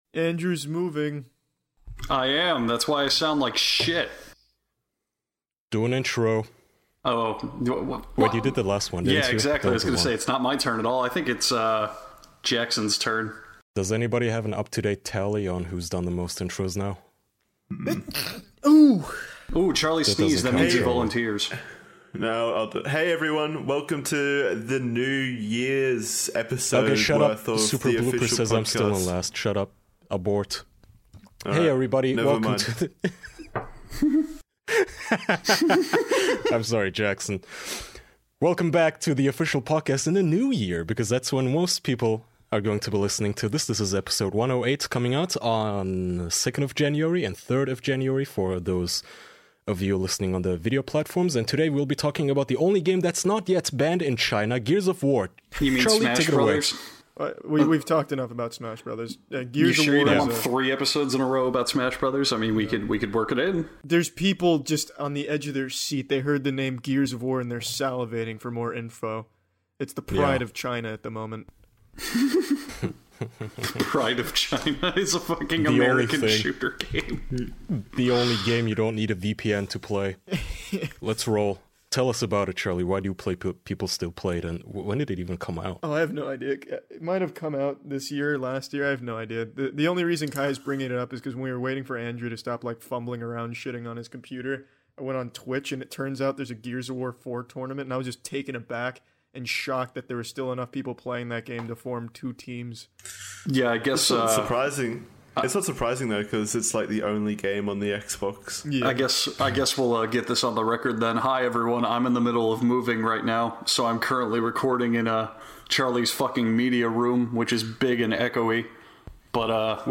Four close man friends gather around to be flat earthers.